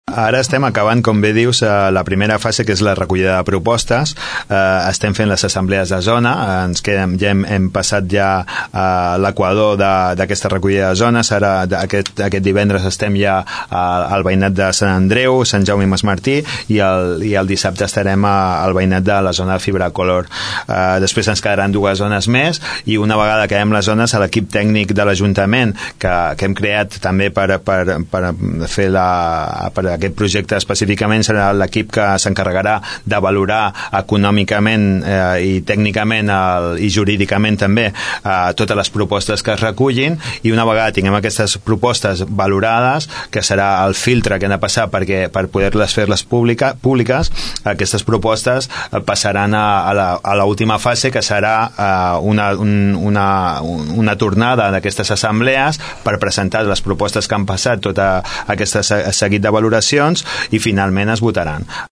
Ho explica el regidor Giralt.